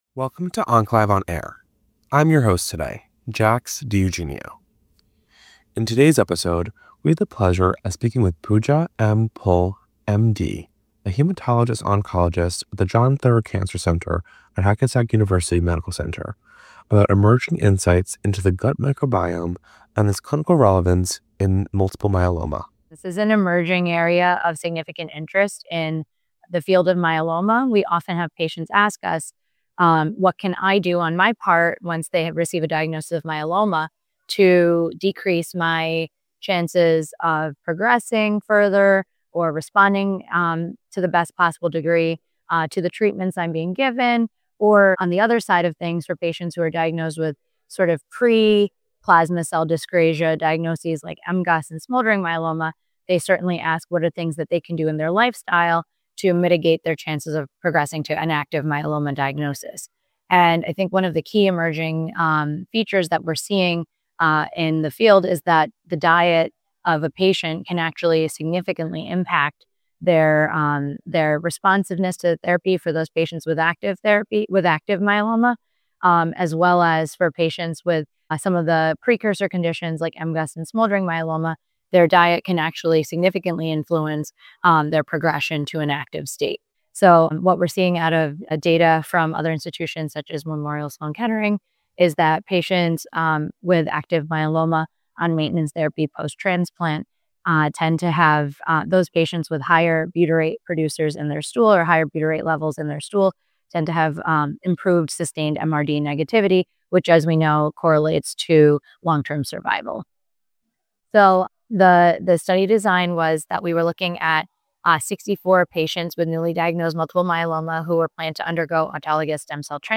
In our exclusive interview